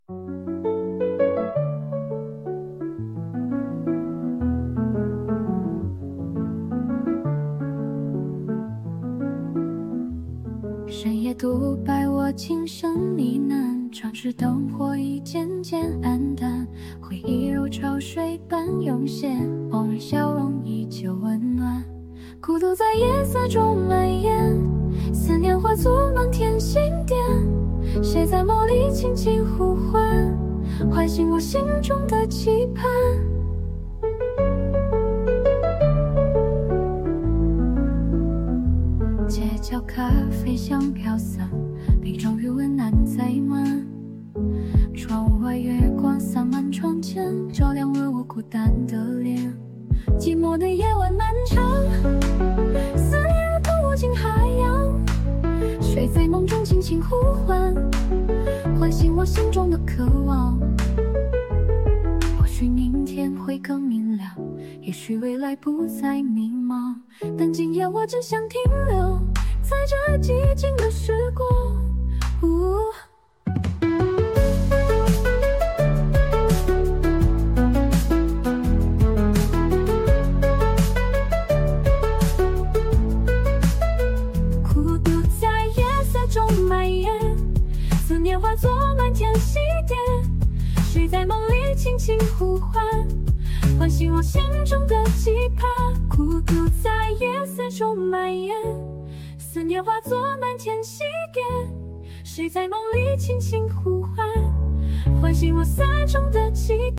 虽然提示词很简单，但生成的歌曲在中文咬字发音上已经非常准确了，甚至超过了某些AI语音生成器。
女声版：
这首歌曲以“孤独、思念”为主题，旋律悠扬，歌词深情。